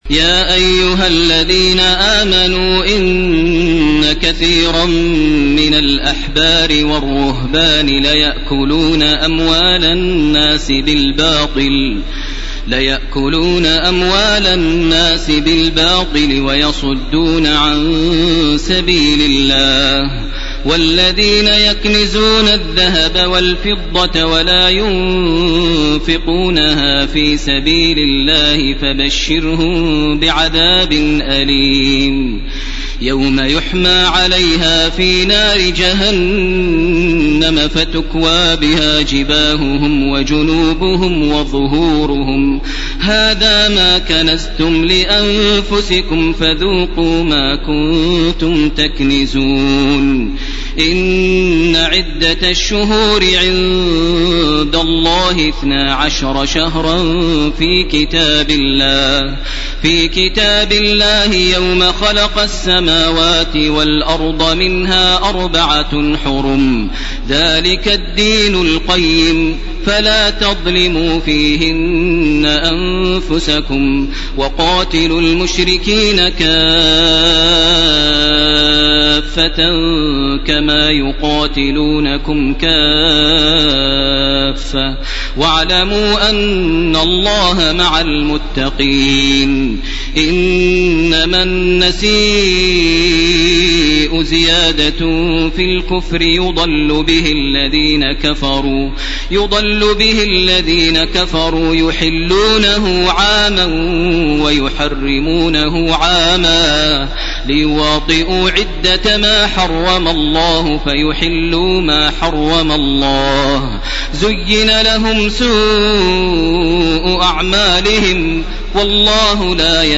ليلة 10 رمضان لعام 1431 هـ من الآية 34 من سورة التوبة وحتى الآية 93 من سورة التوبة. > تراويح ١٤٣١ > التراويح - تلاوات ماهر المعيقلي